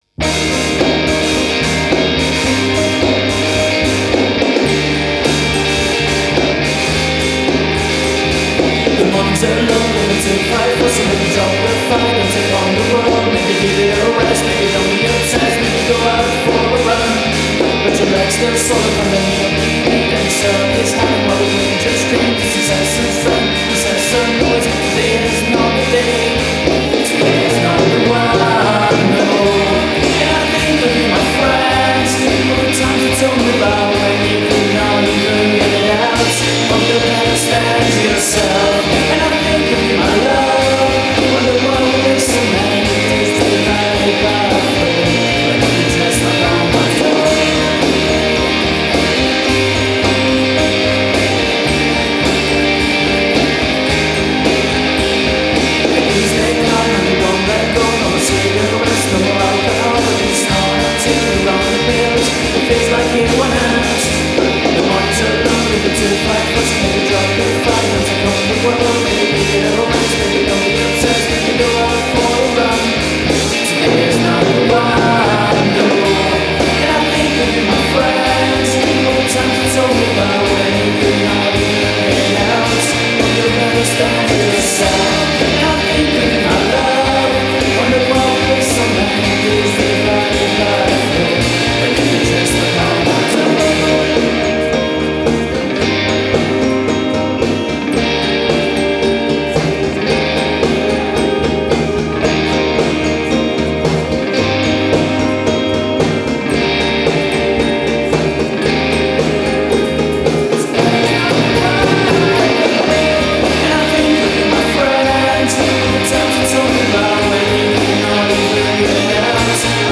vocals, guitars, keyboards, drums, bass
percussion